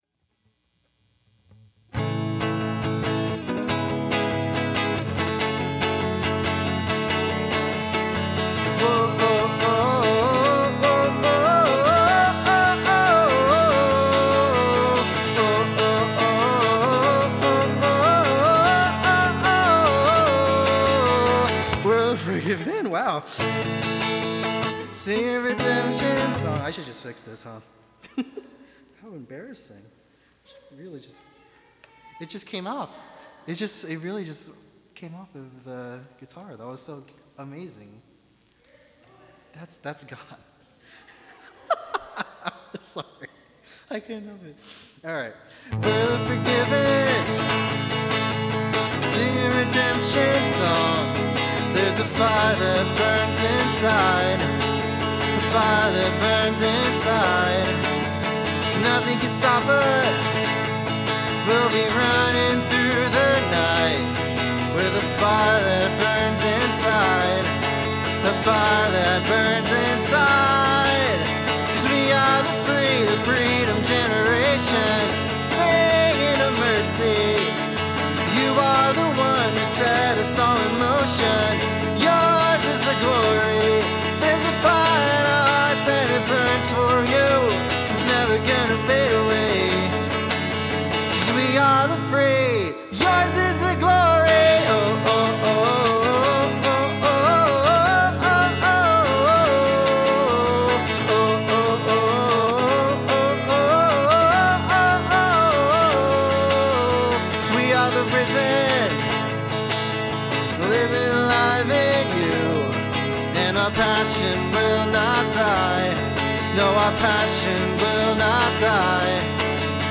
Music form Summer Camp